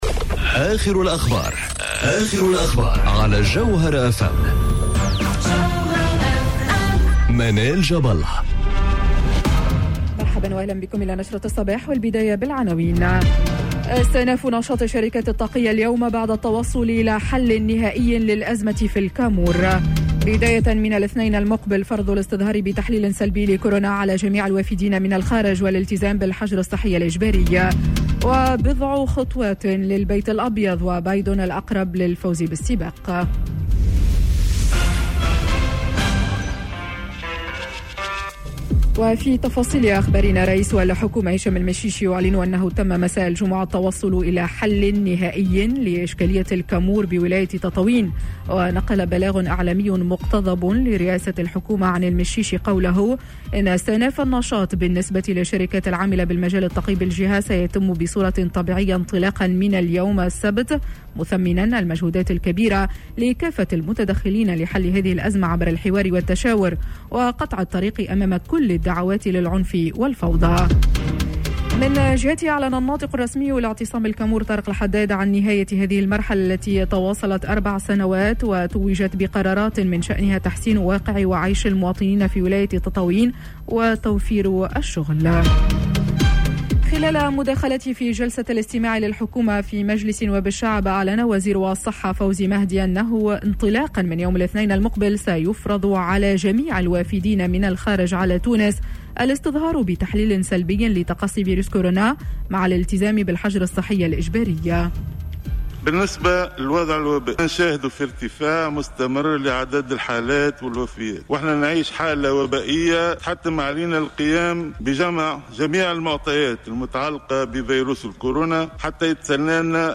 نشرة أخبار السابعة صباحا ليوم الجمعة 07 نوفمبر 2020